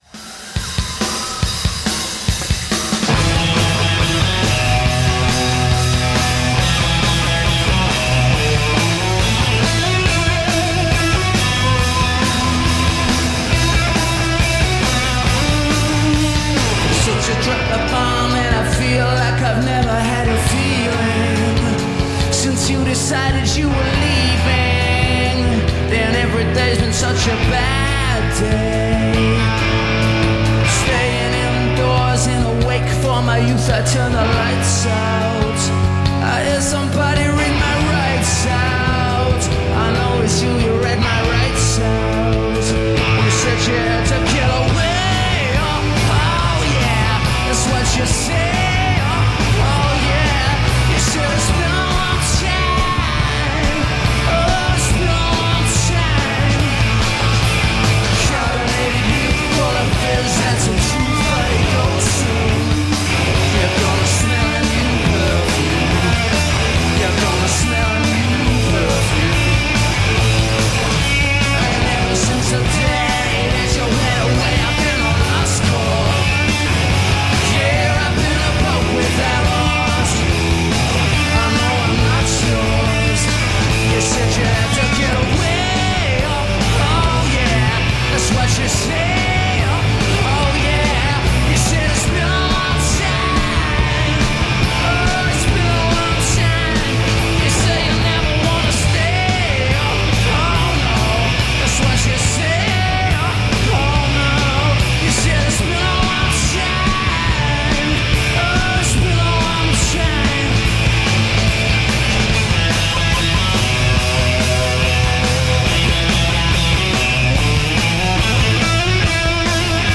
recorded live at Cardiff University, 2000
British indie band